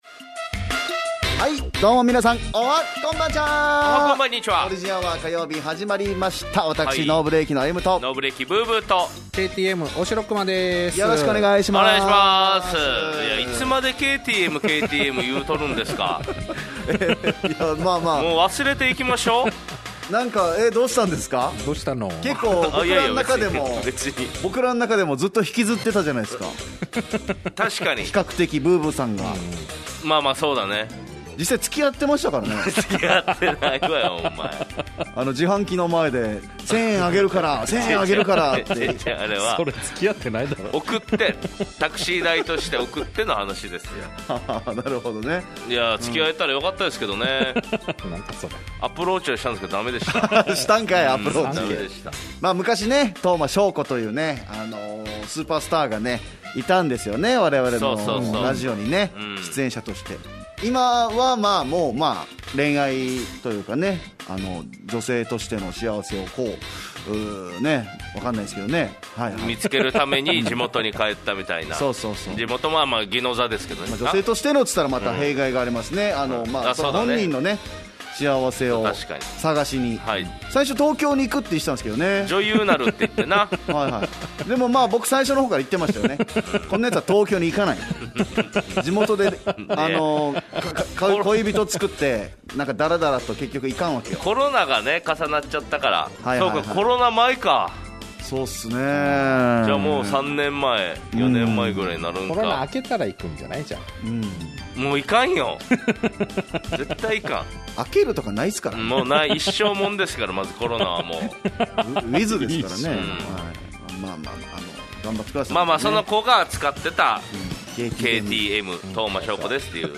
【良音再UP】